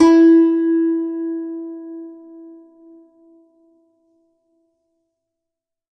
52-str11-zeng-e3.aif